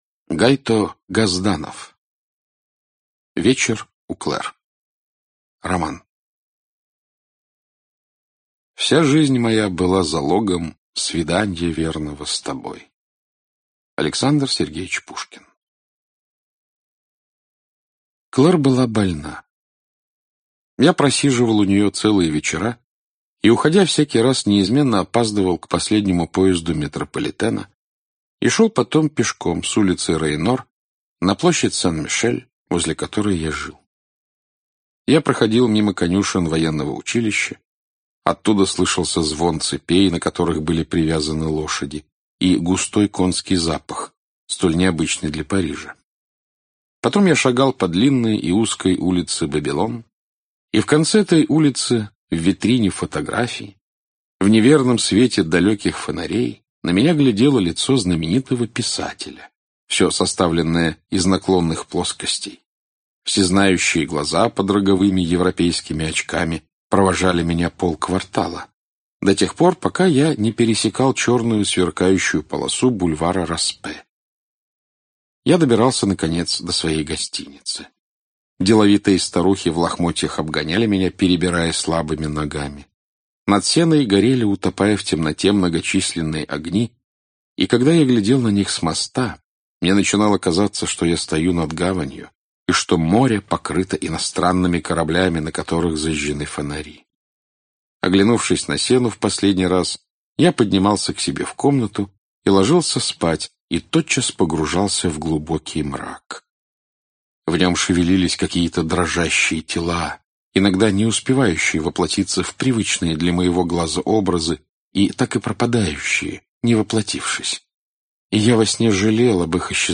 Aудиокнига Вечер у Клэр Автор Гайто Газданов Читает аудиокнигу Александр Клюквин.